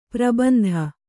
♪ prabandha